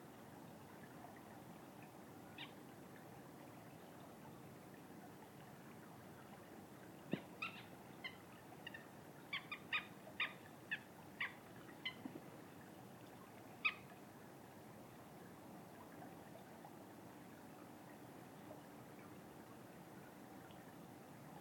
Red List Thirty Five – Black-tailed Godwit
My old favourite source of all things bird, the Crossley Guide, refers to the Black-tailed Godwit as ‘not noisy, making the odd whining ‘wicka‘.
These birds were recorded in Ireland by Irish Wildlife Sounds. The bird sounds more like a small dog to me!